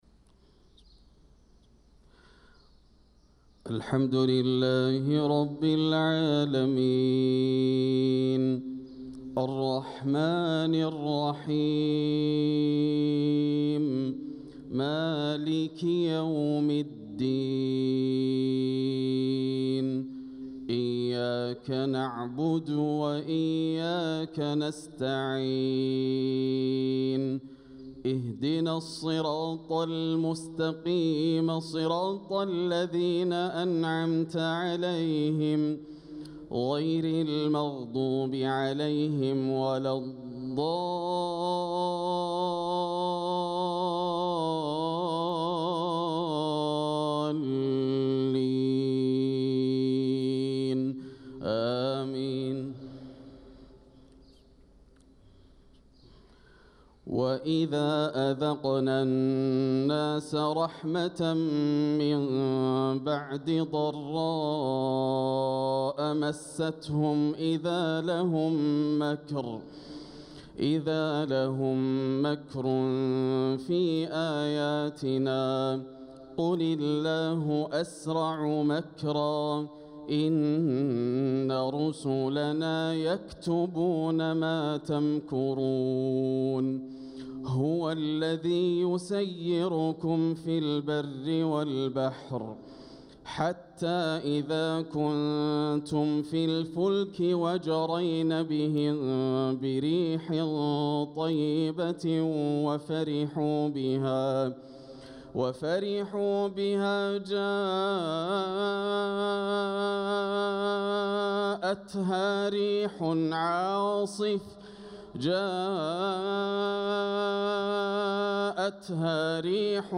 صلاة الفجر للقارئ ياسر الدوسري 15 صفر 1446 هـ
تِلَاوَات الْحَرَمَيْن .